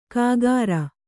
♪ kāgāra